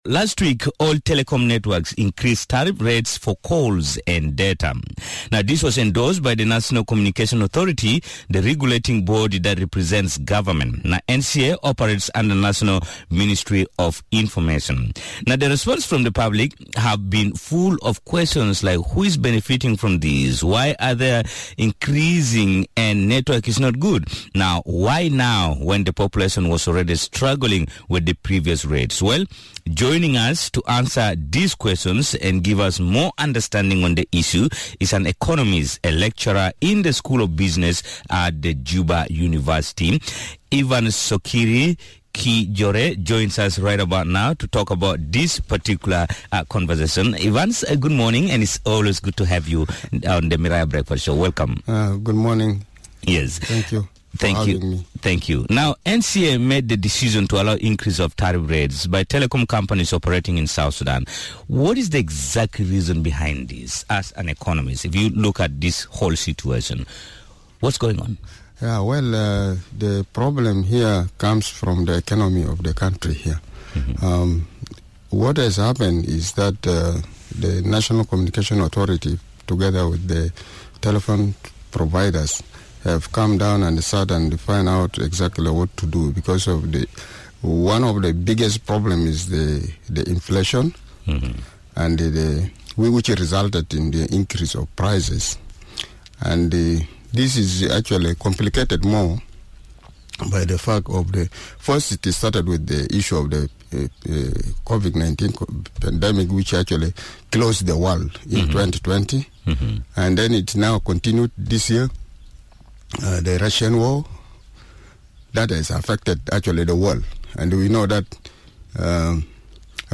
spoke to an economist